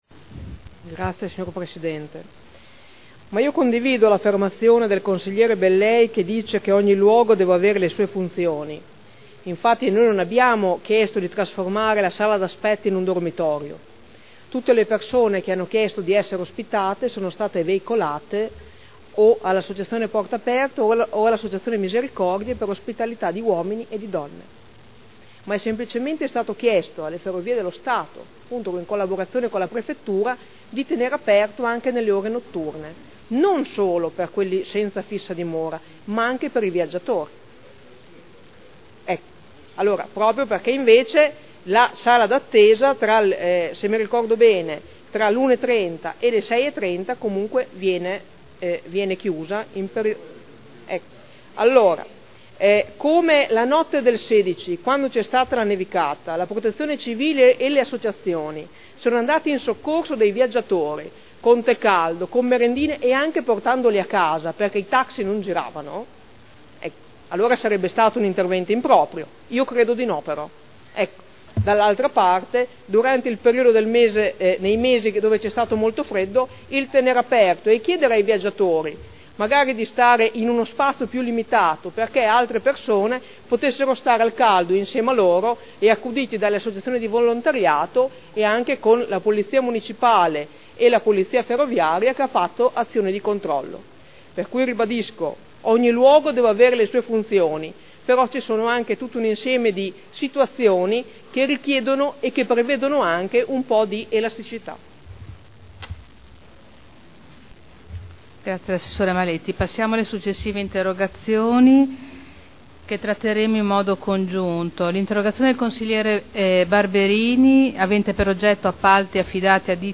Seduta del 14/03/2011. Dibattito su interrogazione dei consiglieri Sala e Rocco (P.D.) avente per oggetto: “Emergenza freddo – sala di attesa della Stazione senza riscaldamento” – Primo firmatario consigliera Sala (presentata il 30 dicembre 2010 – in trattazione il 14.3.2011).